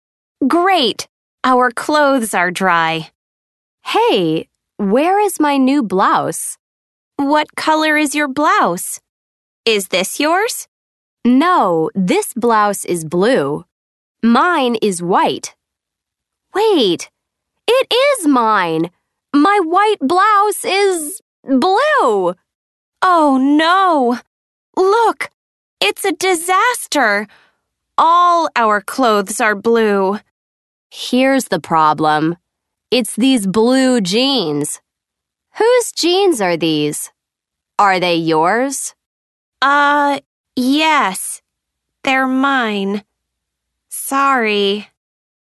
Conversation-are-these-yours.mp3